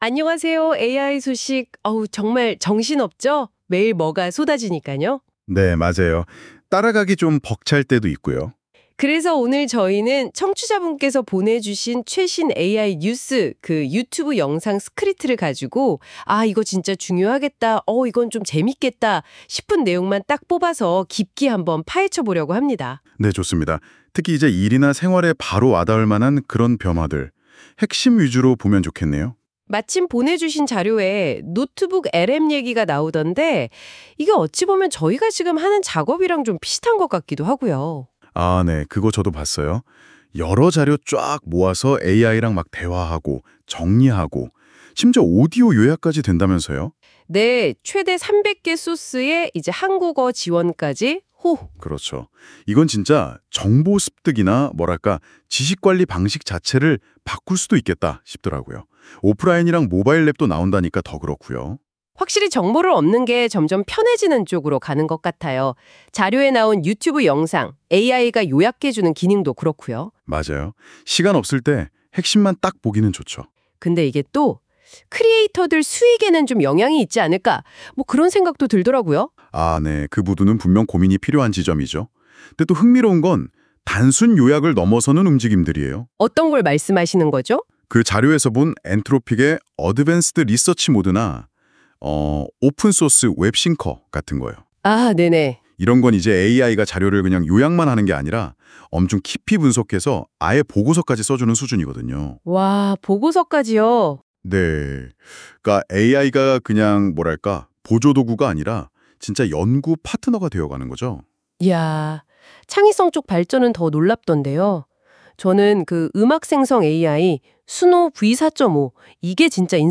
• 라디오 스타일 음성: 두 AI 호스트가 “티키타카” 대화로 자연스럽게 전달.
AI 음성이 어색하다? 두 호스트의 대화는 사람처럼 자연스럽고 기억에 쏙!
• 한국어 퀄리티: “어라, 사람 같네!” 할 정도로 자연스러움.